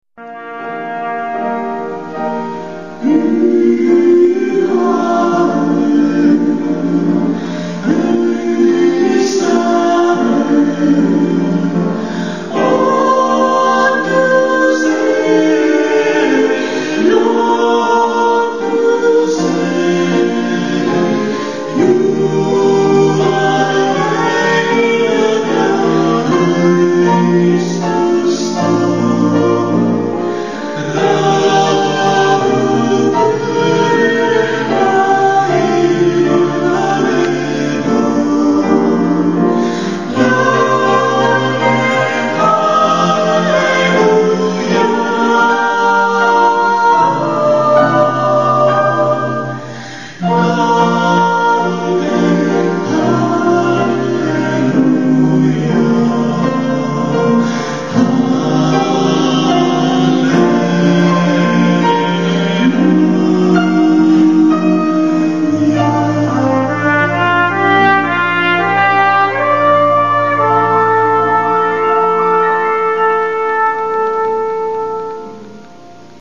Here’s a recording of a well-known Christmas carol in a mystery language.